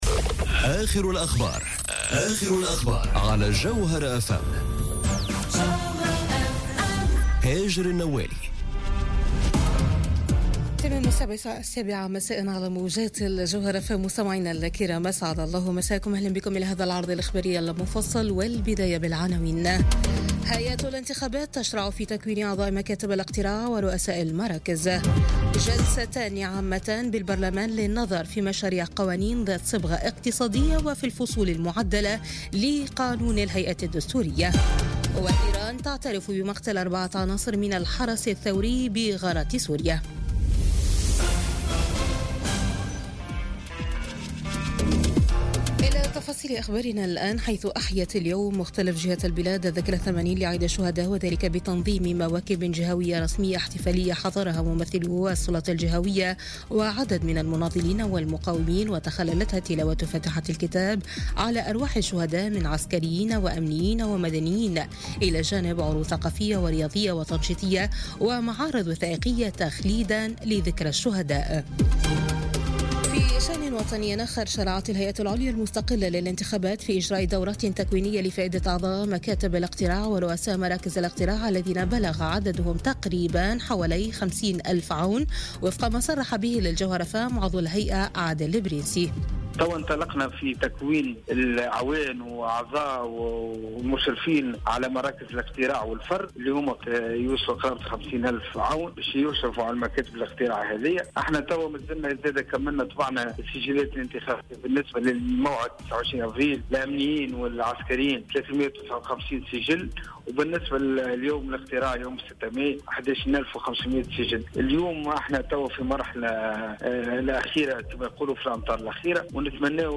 نشرة أخبار السابعة مساء ليوم الاثنين 9 أفريل 2018